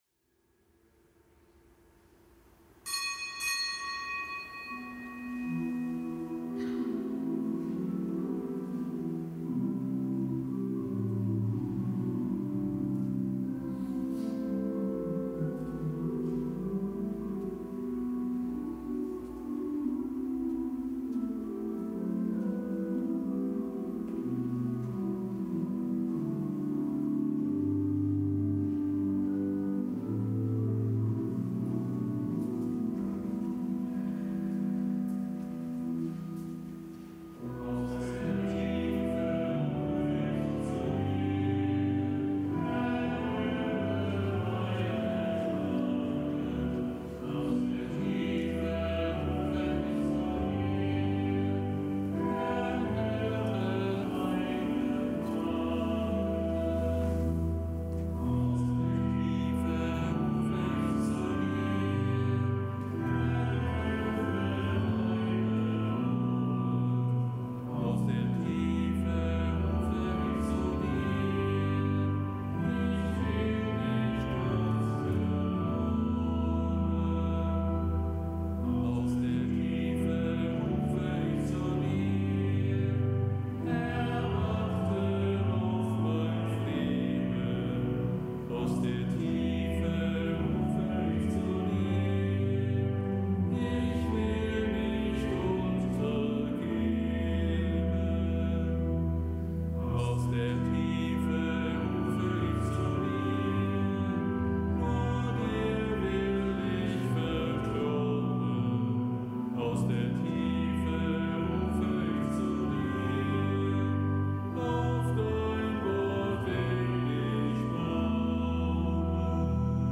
Kapitelsmesse aus dem Kölner Dom am Montag der vierten Fastenwoche. Zelebrant: Weihbischof Ansgar Puff